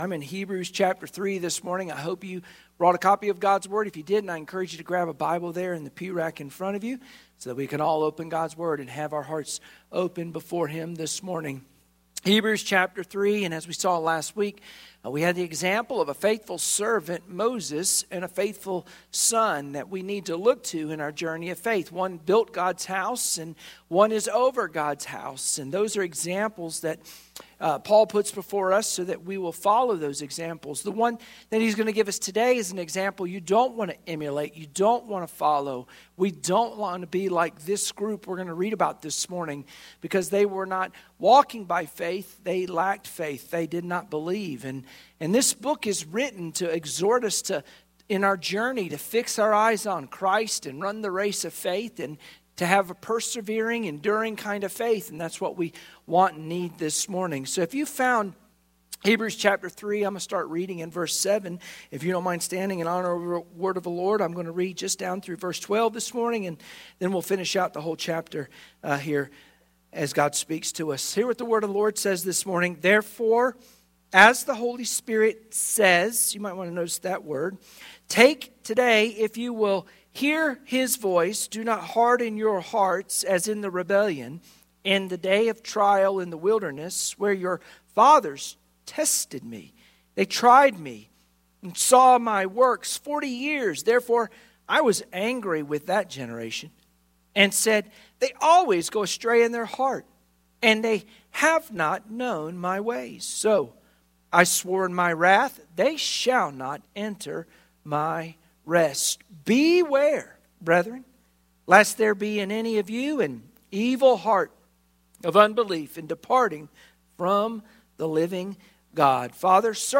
Hebrews 3:7-19 Service Type: Sunday Morning Worship Share this